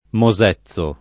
[ mo @$ZZ o ]